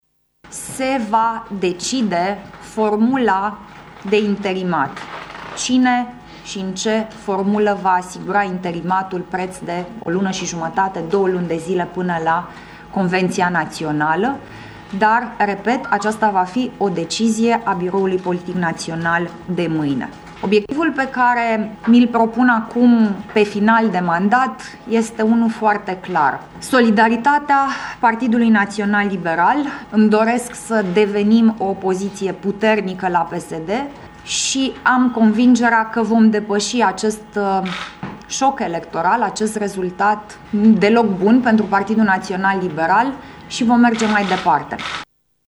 Președintele PNL, Alina Gorghiu, a declarat, în urmă cu puțin timp, că își va depune mandatul de la conducerea partidului și că acest gest a fost anunțat și de alți lideri liberali: